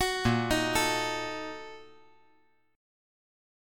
Listen to BbM7sus4#5 strummed